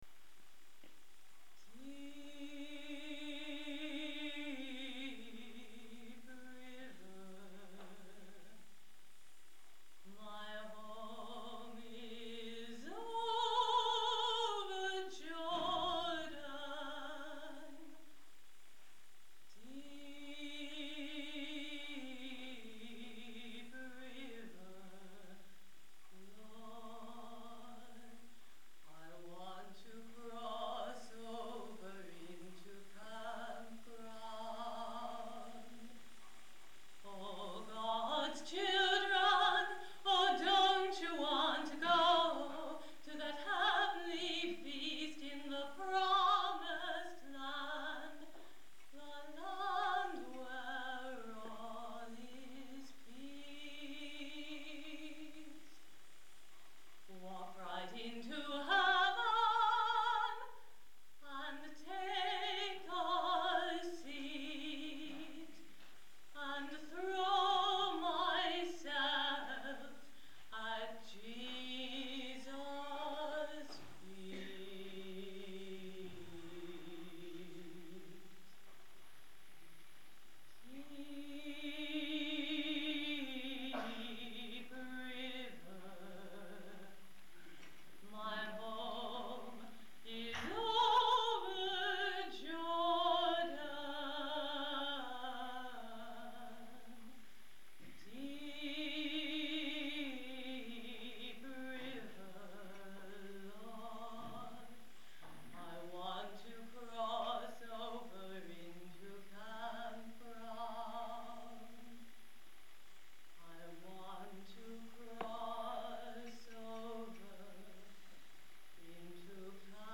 • the slow, sustained, long-phrase melody
Listen to this slow, sustained, long-phase melody by Spiritual Workshop Paris.